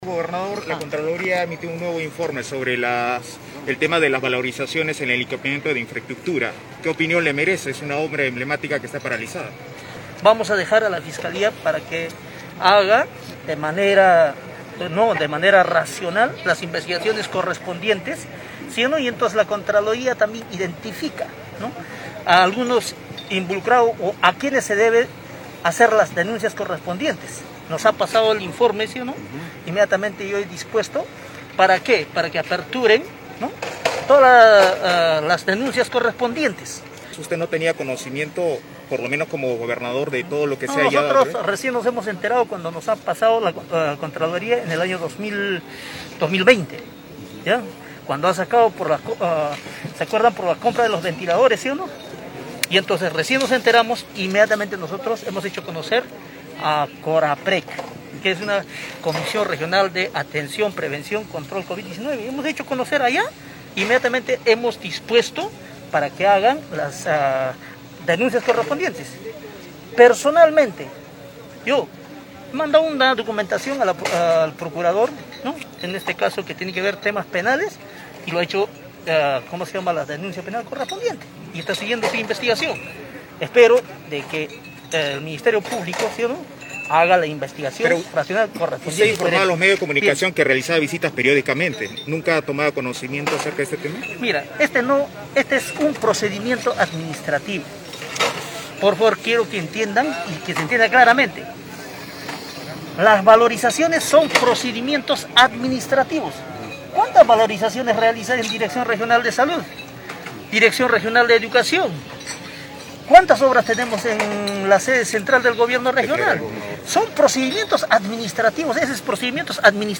El gobernador regional de Tacna reapareció ante los medios de comunicación la mañana de hoy 2 de febrero durante una entrega de ambulancias al sector salud.